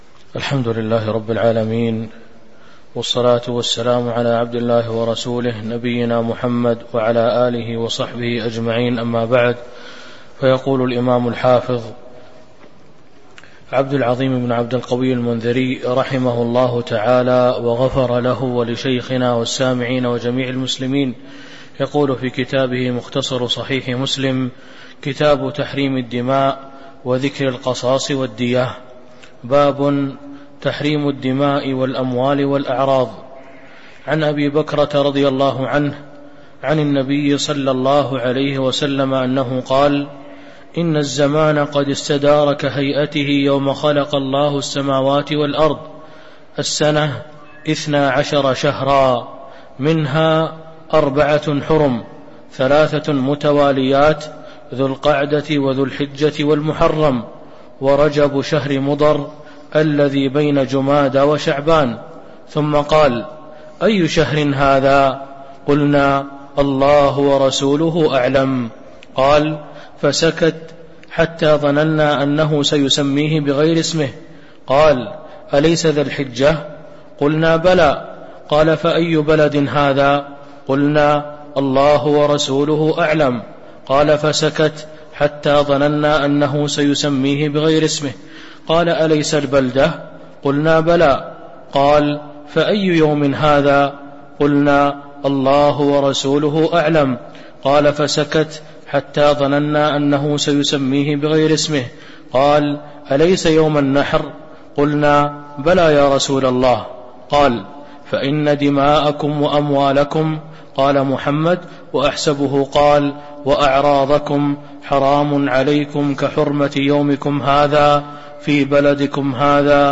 تاريخ النشر ٢٩ صفر ١٤٤٣ هـ المكان: المسجد النبوي الشيخ: فضيلة الشيخ عبد الرزاق بن عبد المحسن البدر فضيلة الشيخ عبد الرزاق بن عبد المحسن البدر باب تحريم الدماء والأموال والأعراض (01)